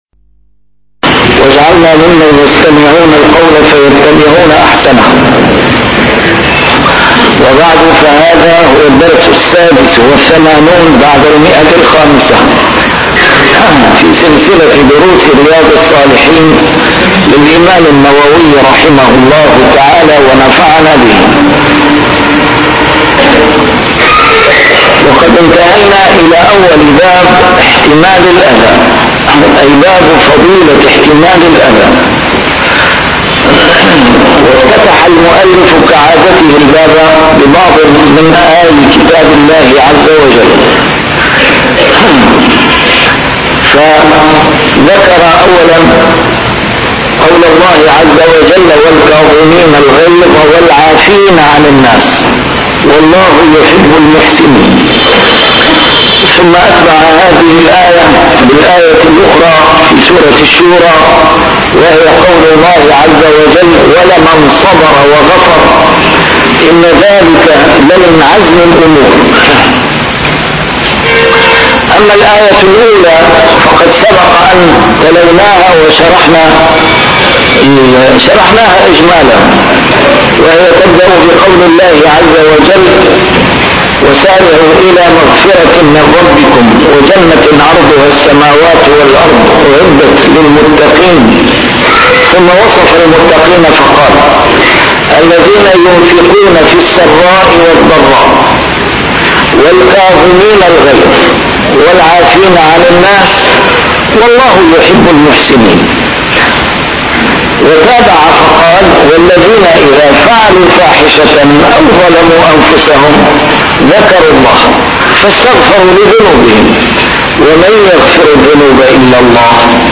A MARTYR SCHOLAR: IMAM MUHAMMAD SAEED RAMADAN AL-BOUTI - الدروس العلمية - شرح كتاب رياض الصالحين - 586- شرح رياض الصالحين: احتمال الأذى